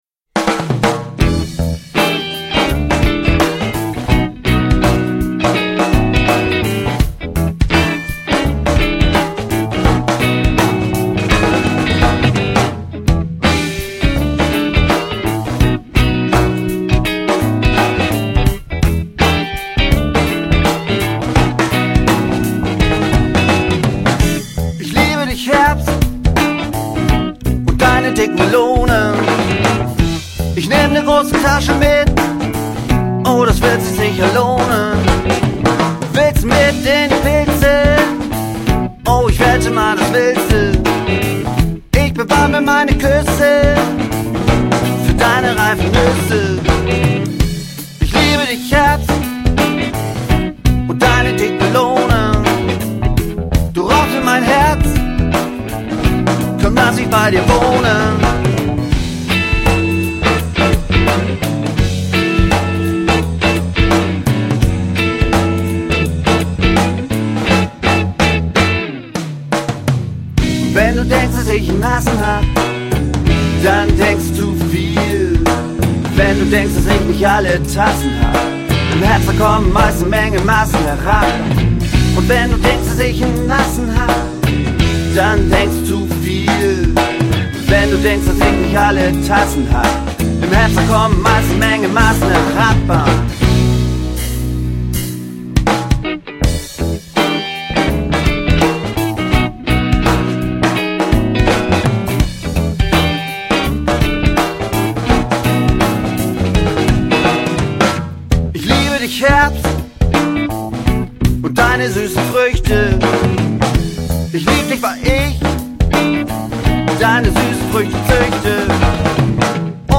Gitarre
Bass
Posaune